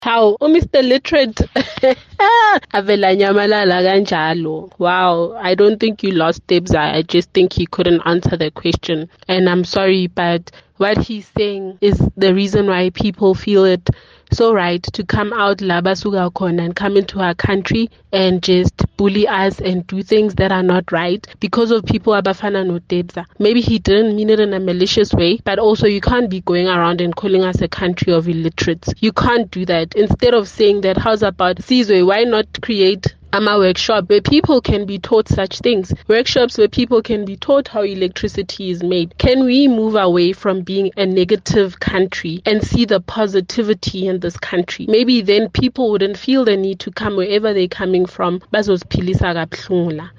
However, as was evident by listener’s views on Kaya Drive, not many share the President’s sentiments.